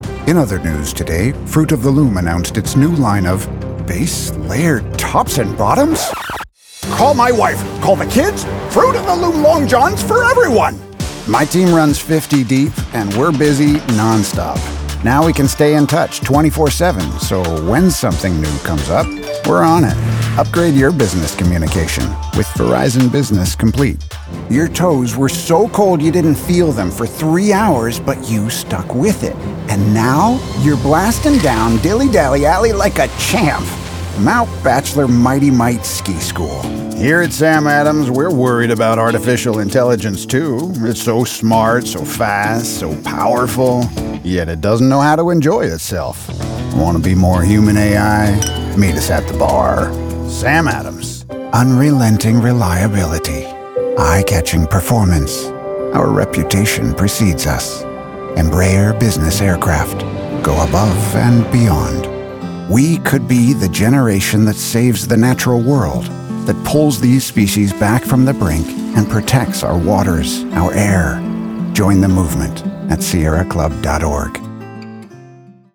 Commercial Demo:Fatherly, Friendly, Conversational, Luxurious
I have the energetic voice of a man grateful for the bounty life offers, the gravelly voice of a lifelong outdoorsman, the deep voice of a man who has raised children, the generous voice of a teacher who has forgiven thousands of students, and most of all the ironic voice of a man who has forgiven himself for countless stumbles.